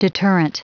Prononciation du mot deterrent en anglais (fichier audio)
Prononciation du mot : deterrent